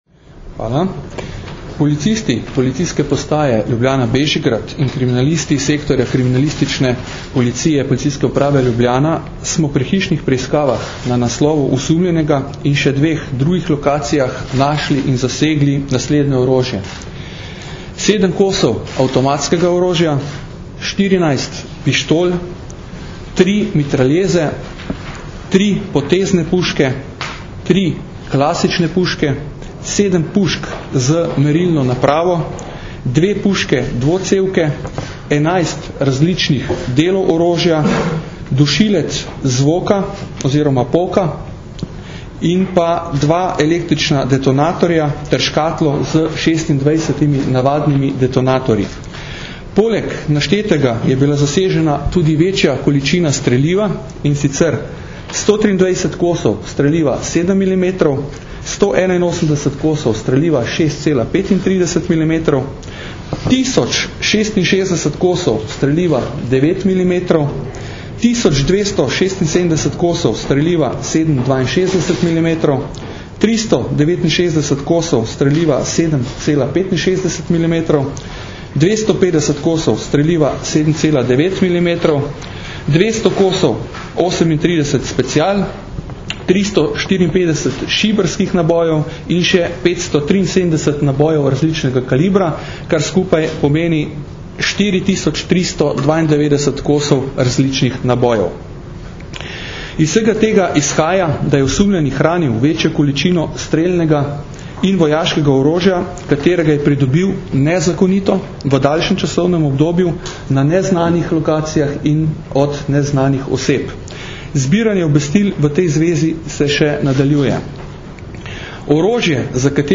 Zaseg večje količine orožja in streliva, problematika ilegalne trgovine in prometa z orožjem - informacija z novinarske konference